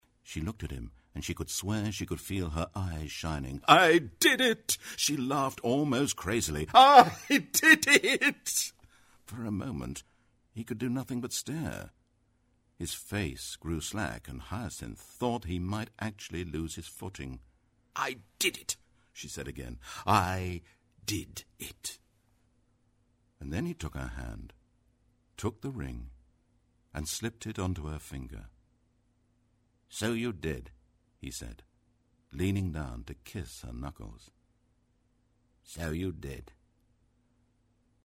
britisch
Sprechprobe: eLearning (Muttersprache):
english (uk) voice over artist.